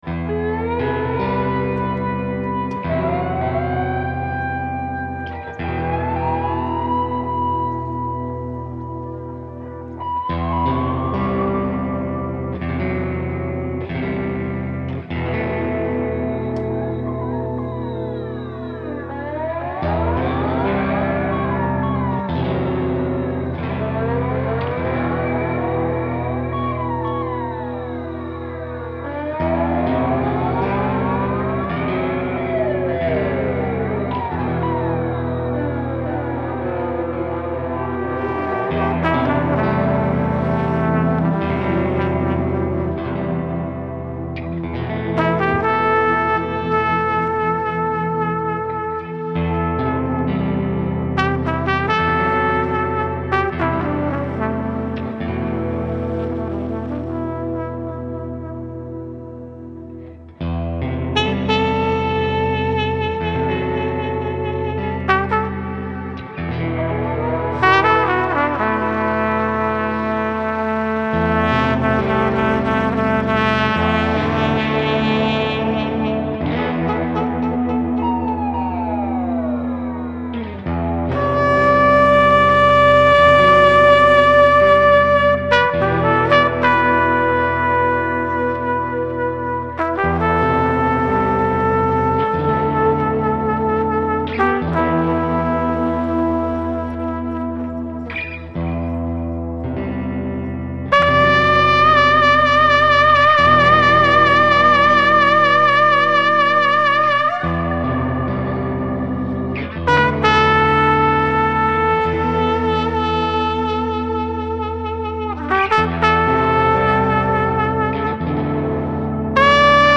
improvised jam music